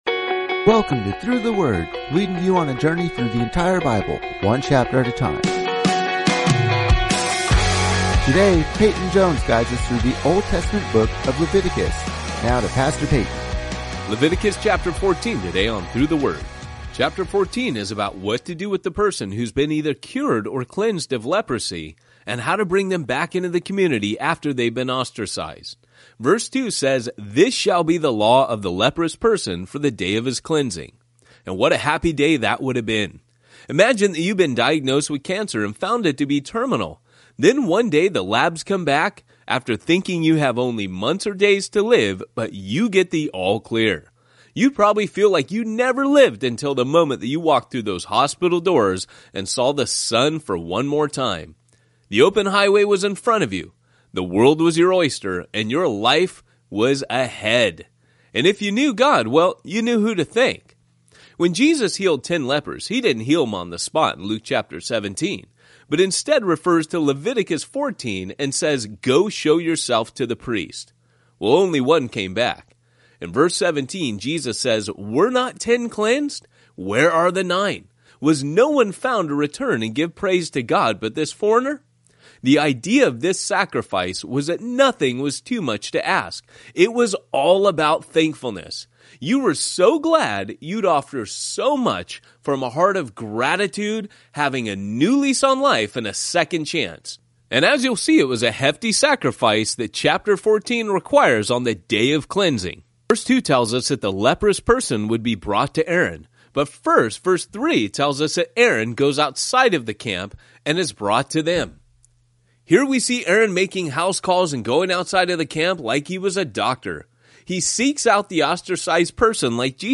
19 Journeys is a daily audio guide to the entire Bible, one chapter at a time. Each journey takes you on an epic adventure through several Bible books as your favorite pastors explain each chapter in under ten minutes.